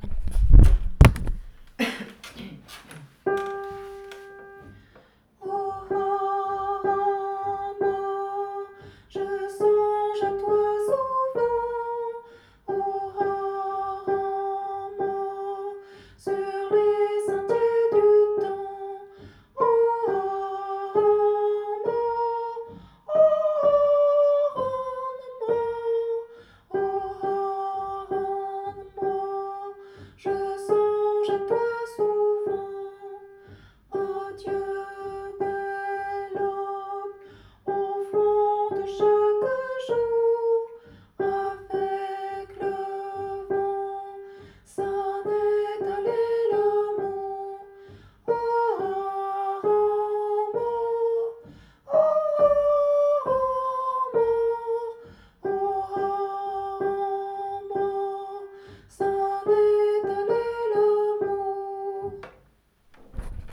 oh_arranmore_tenor.wav